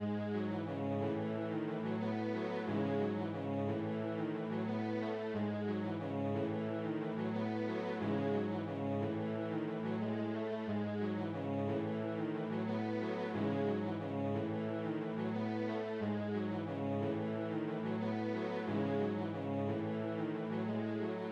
嘻哈弦乐 专注 90 BPM
Tag: 90 bpm Hip Hop Loops Strings Loops 3.60 MB wav Key : C